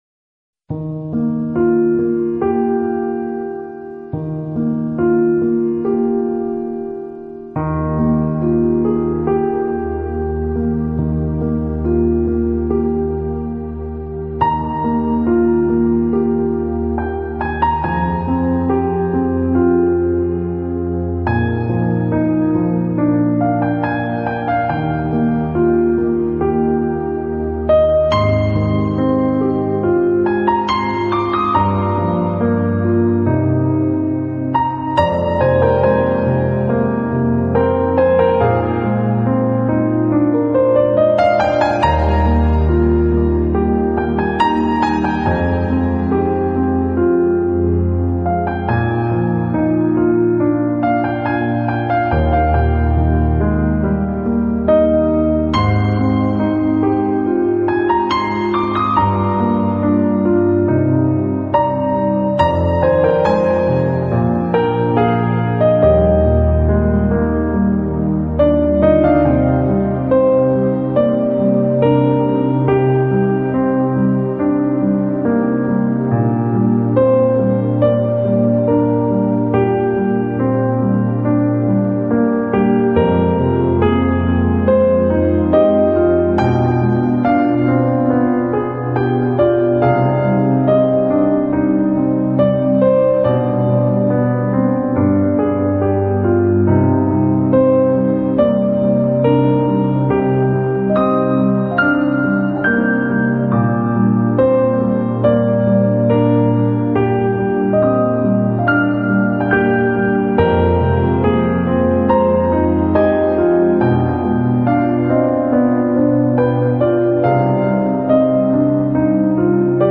Style: Neo-classic, Piano solo